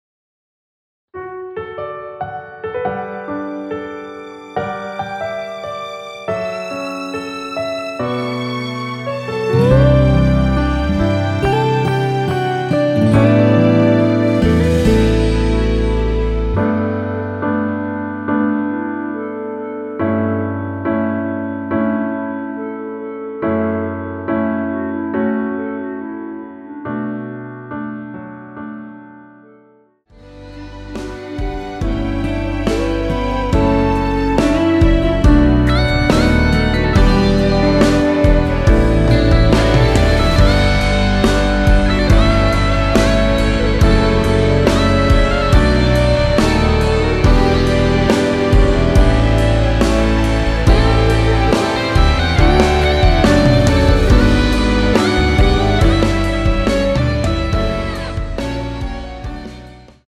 원키에서(-1)내린 멜로디 포함된 MR입니다.
D
앞부분30초, 뒷부분30초씩 편집해서 올려 드리고 있습니다.